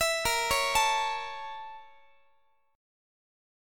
Listen to BbmM7b5 strummed